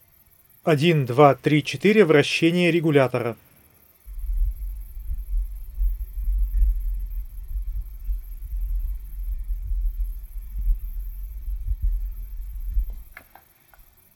Видно, что шум появляется, но в целом его уровень не критичен — можно «замаскировать» лишние звуки голосом.
Пример записи: вращение регулятора